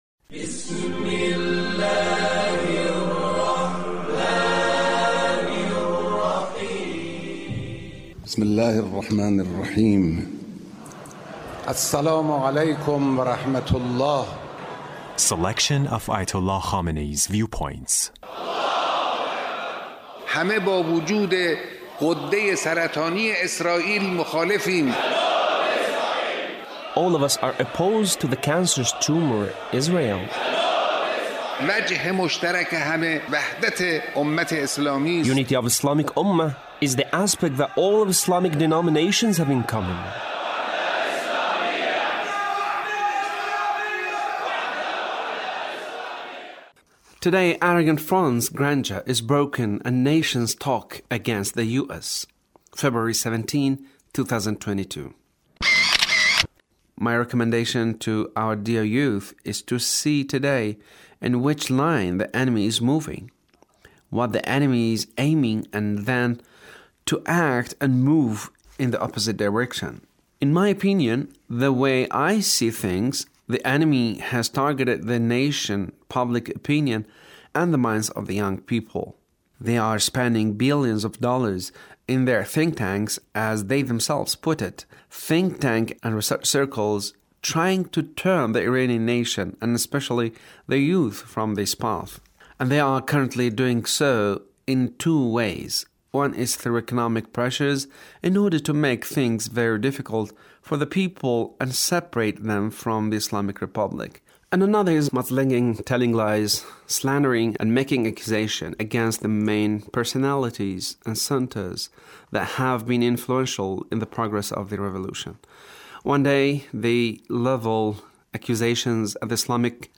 The Leader's speech on Imam Khomeini and His will